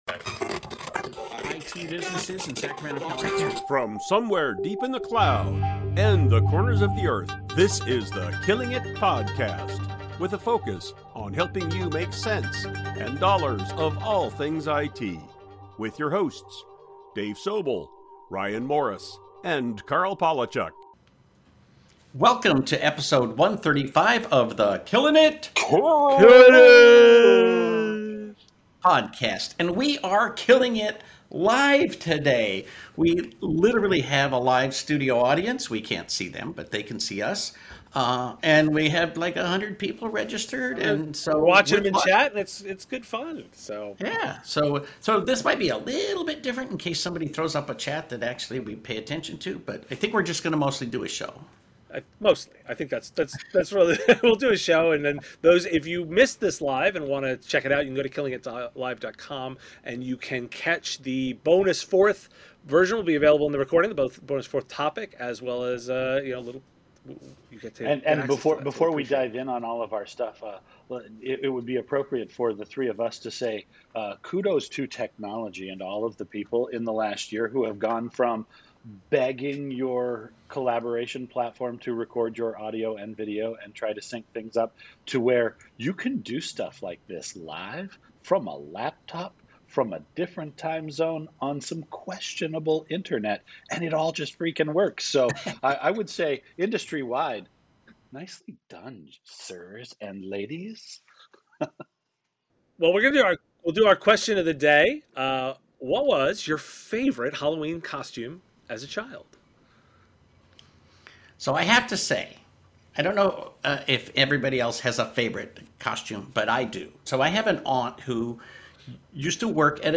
This special edition of The Killing IT Podcast was recorded before a live audience in a special presentation made possible by Cisco.